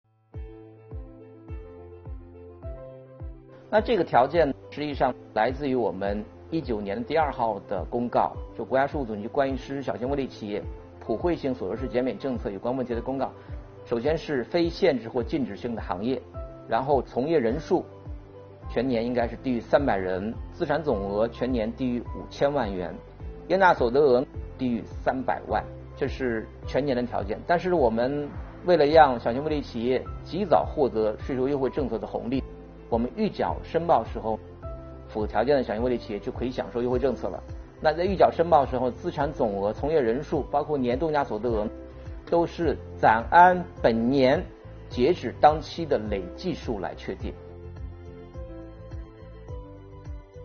近日，国家税务总局推出最新一期“税务讲堂”课程，税务总局所得税司副司长王海勇介绍并解读了小型微利企业和个体工商户所得税优惠政策。